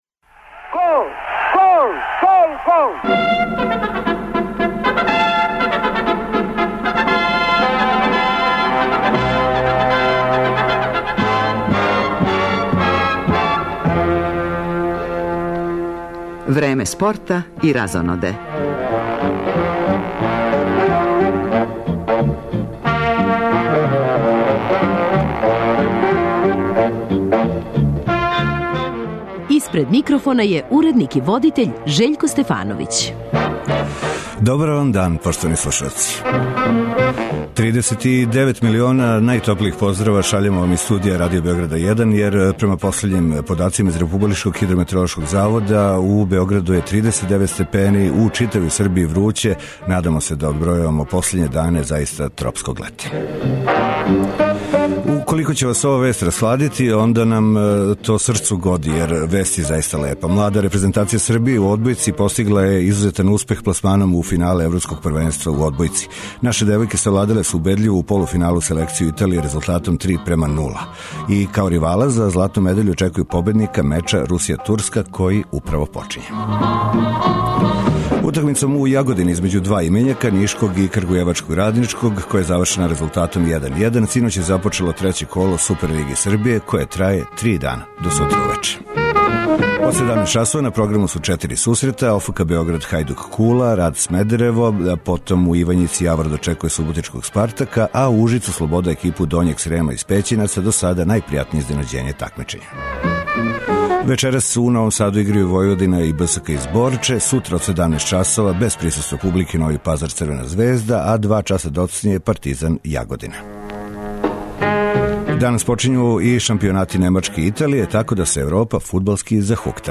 Породични радио магазин бави се, између осталог, ситуацијом у два наша највећа фудбалска колектива, која је далеко од добре.